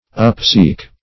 Search Result for " upseek" : The Collaborative International Dictionary of English v.0.48: Upseek \Up*seek"\, v. i. To seek or strain upward.